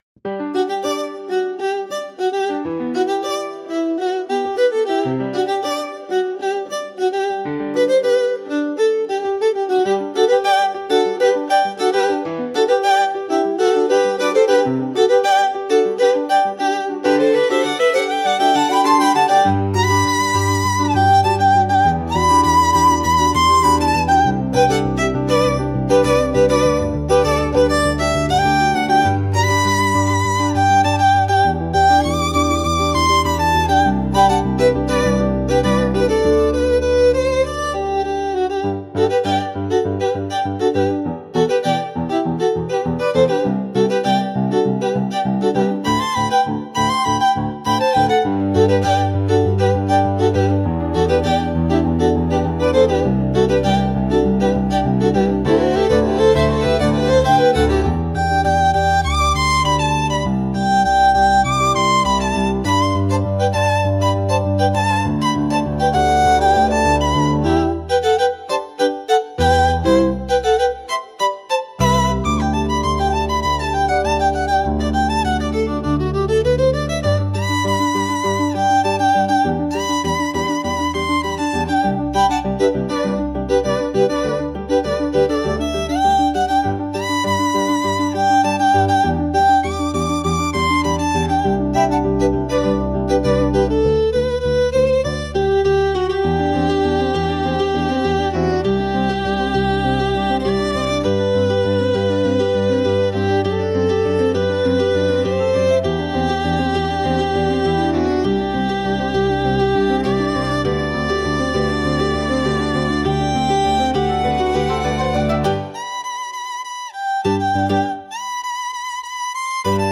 Instrumental / 歌なし
🌍 Elegant, emotional, and full of grace.
A gorgeous, moving piece where elegance and passion coexist.
美しく響くバイオリンとピアノが描く、強くしなやかな決意のダンス。
優雅さと情熱が共存する、華やかで心揺さぶる一曲です。